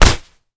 kick_hard_impact_01.wav